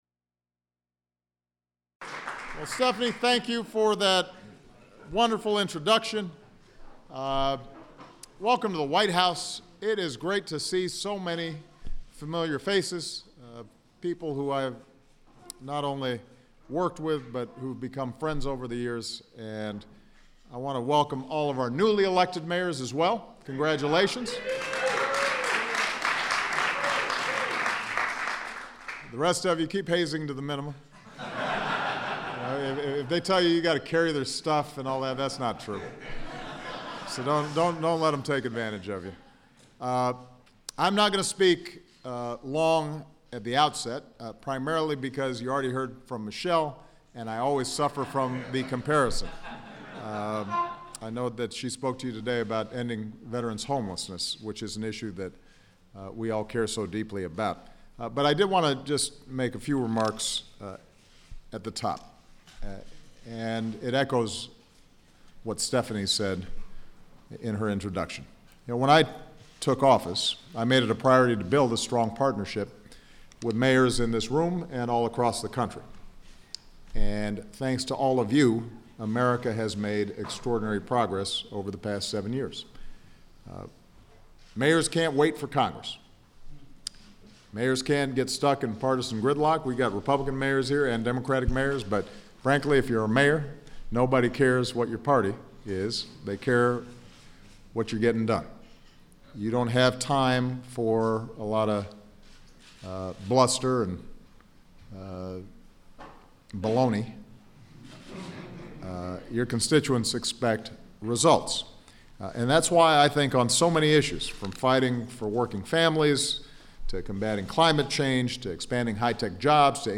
U.S. President Barack Obama speaks at the U.S. Conference of Mayors held in the East Room of the White House